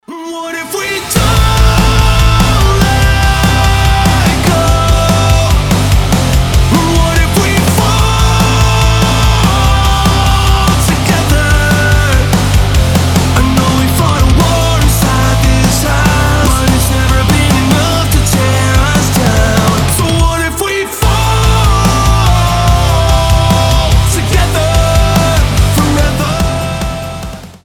громкие
мощные
Alternative Metal